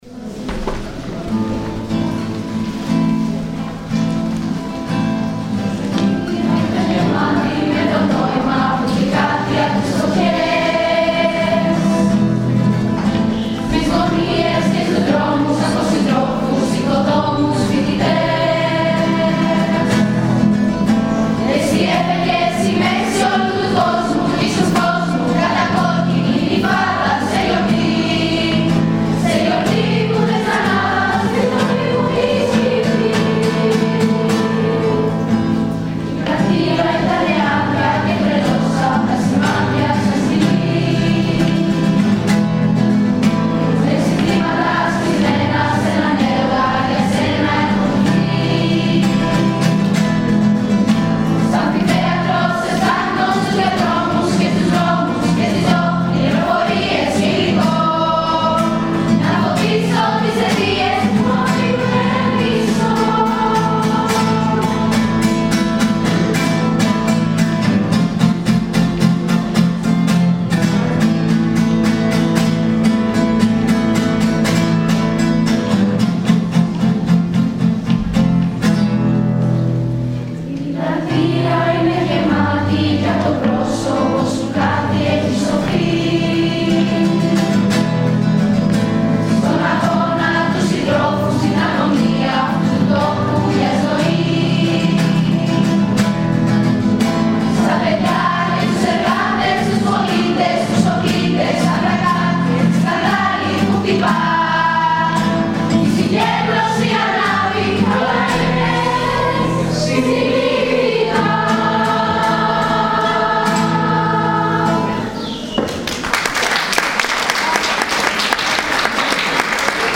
Μια καταπληκτική γιορτή μνήμης για τη 17 Νοέμβρη διοργάνωσαν οι μαθητές του σχολείου μας μαζί με τους καθηγητές τους. Πάρτε μια γεύση ακούγοντας την χορωδία.